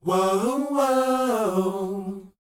WHOA G AD.wav